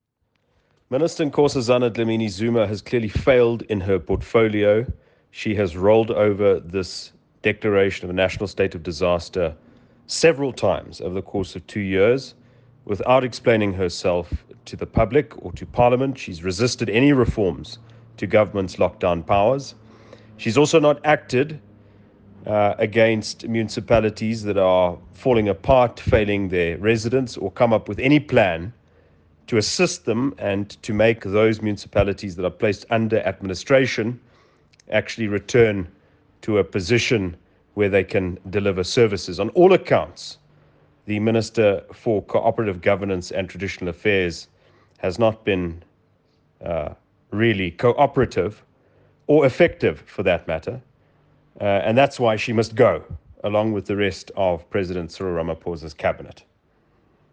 Afrikaans soundbites by Cilliers Brink MP.